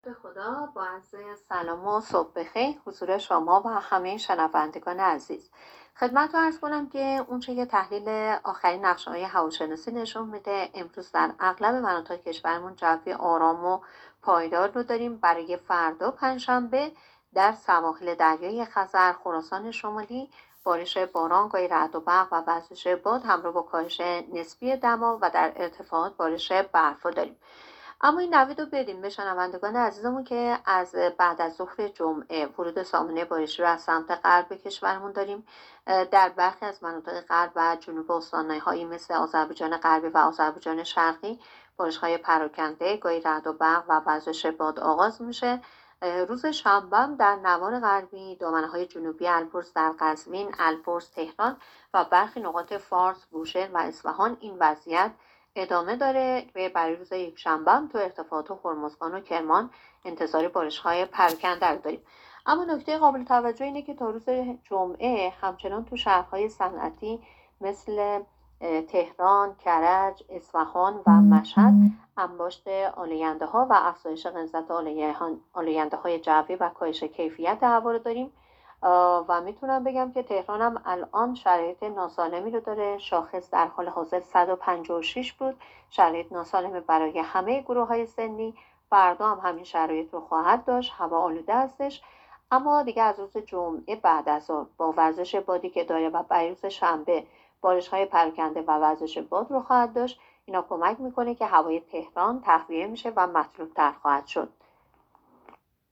گزارش آخرین وضعیت جوی کشور را از رادیو اینترنتی پایگاه خبری وزارت راه و شهرسازی بشنوید.